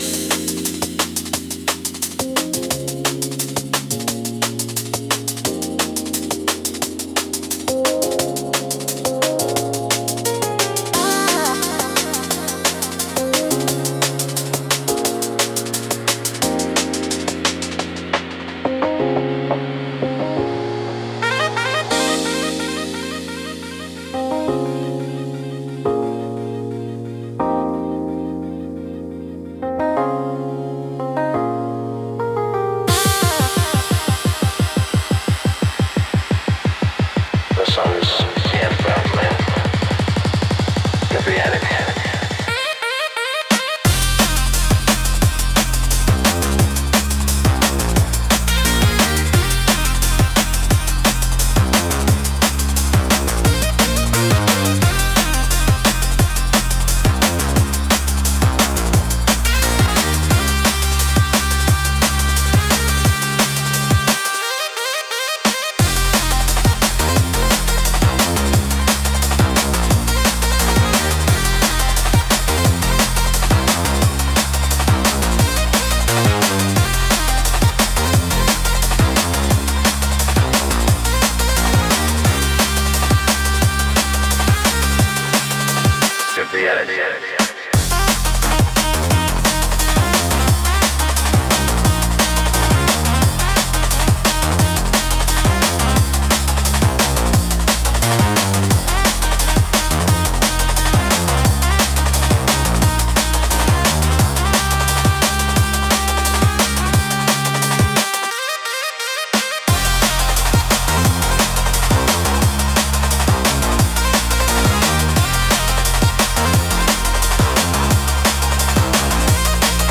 テンポの速さと複雑なリズムで、聴く人の集中力と興奮を引き上げる効果があります。エッジの効いたダイナミックなジャンルです。